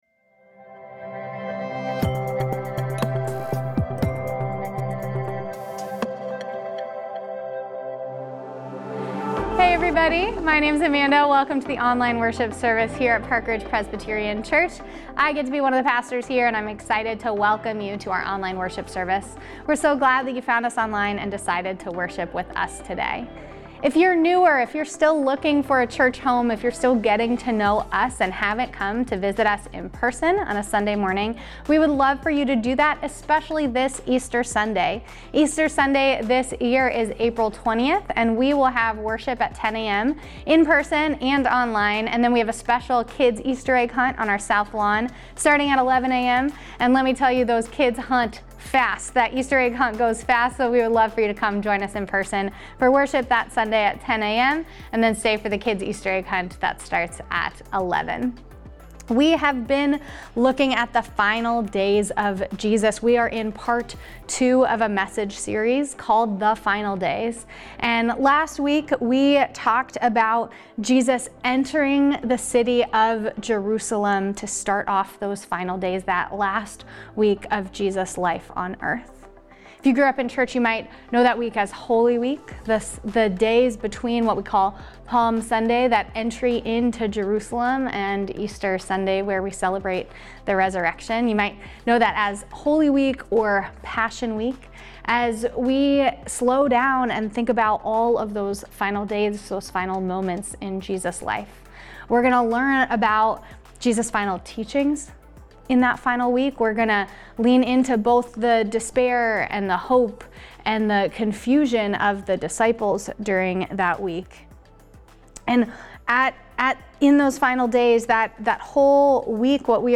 March 30, 2025 Online Worship Service
Mar-30-Online-Worship-Service.mp3